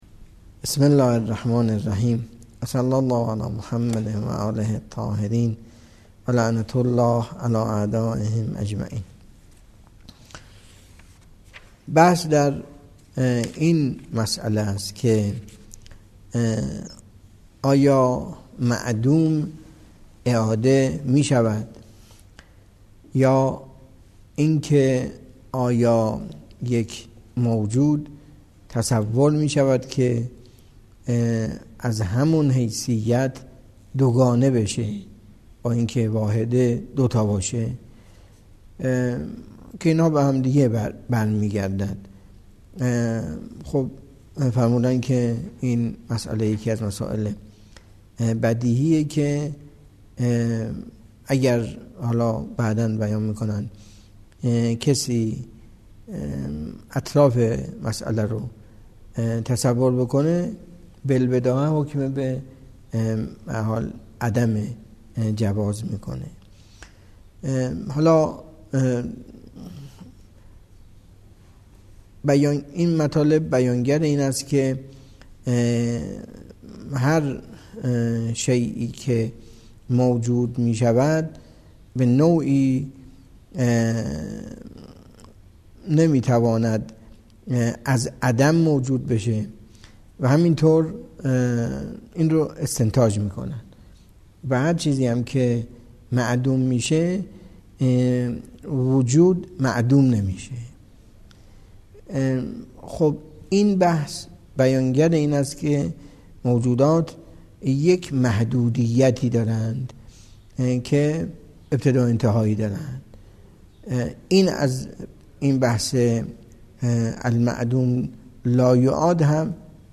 درس فلسفه اسفار اربعه